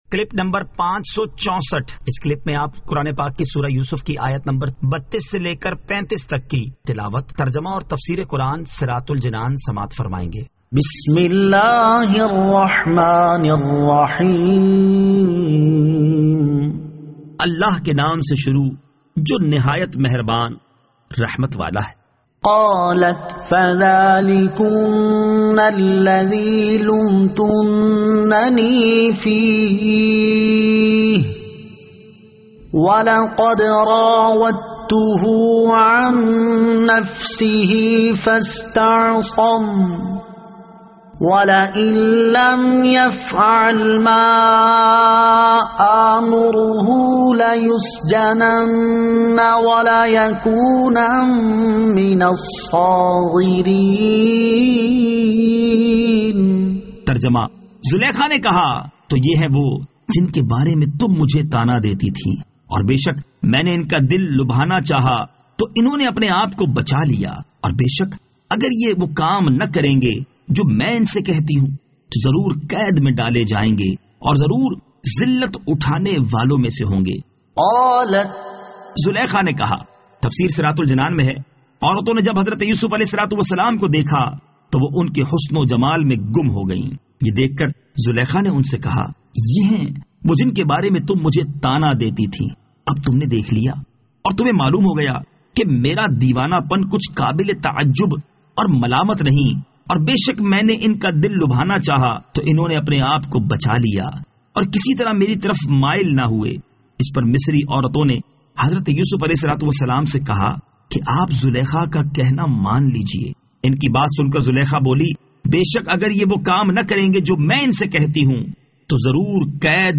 Surah Yusuf Ayat 32 To 35 Tilawat , Tarjama , Tafseer